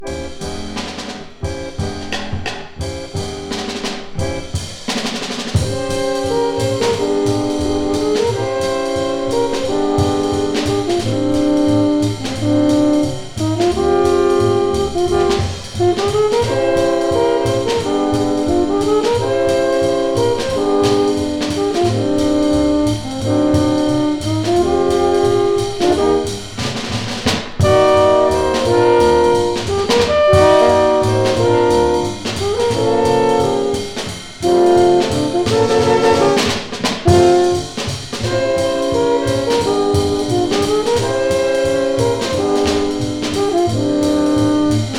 Jazz　USA　12inchレコード　33rpm　Mono